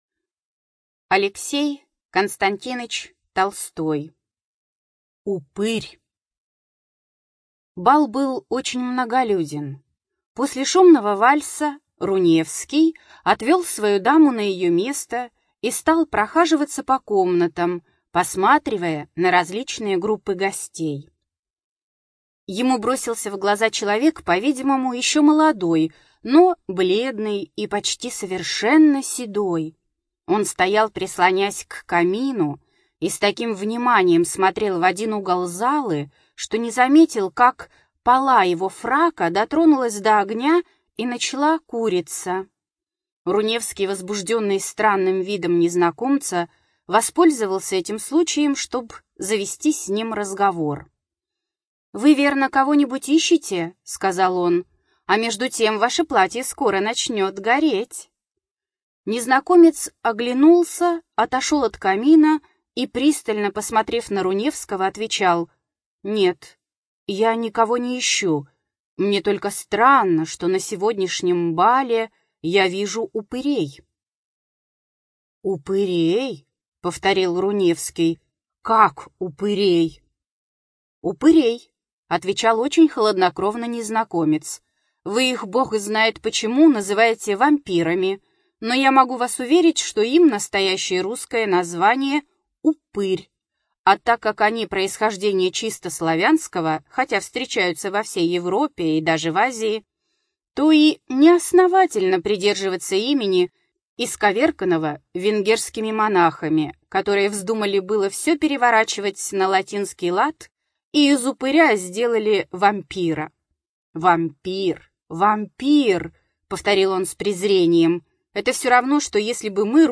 ЖанрУжасы и мистика